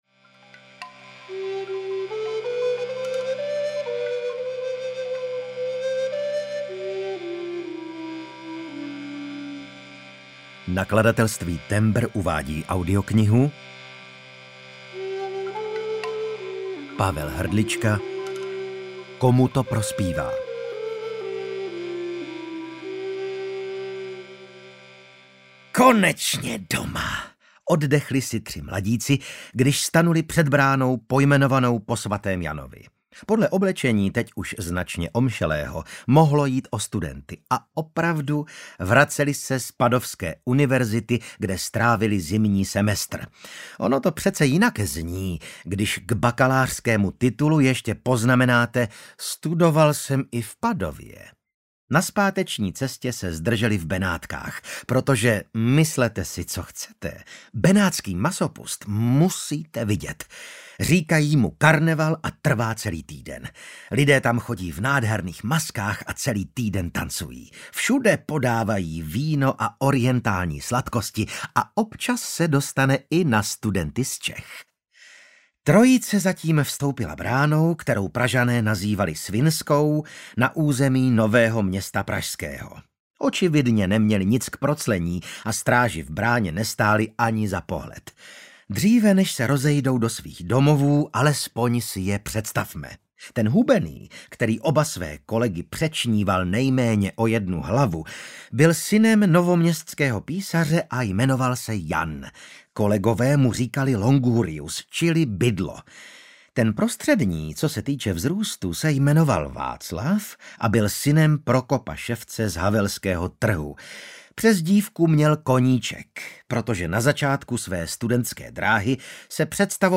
Komu to prospívá audiokniha
Ukázka z knihy
• InterpretVasil Fridrich